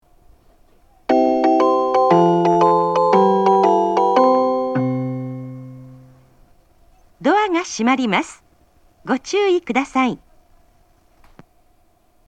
発車メロディー
こちらもスイッチを一度扱えばフルコーラス鳴ります。